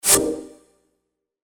/ F｜演出・アニメ・心理 / F-03 ｜ワンポイント1_エフェクティブ
シュン